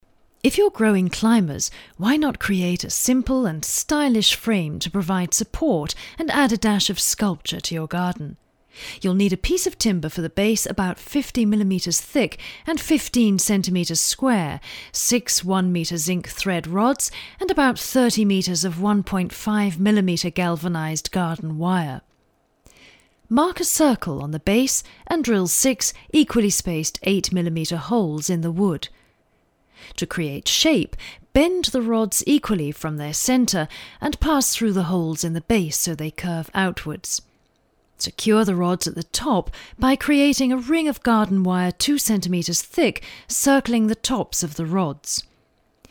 » Englisch US f.
voice123_demo_gardening.mp3